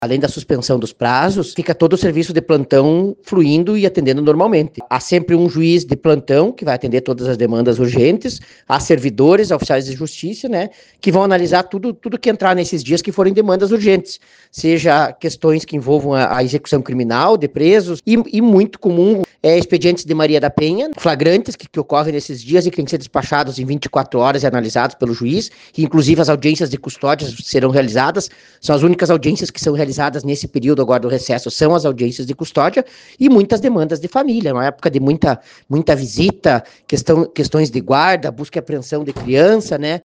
O Juiz da 1ª Vara Criminal do Fórum de Ijuí, Eduardo Giovelli, explica que ficarão suspensos os prazos processuais.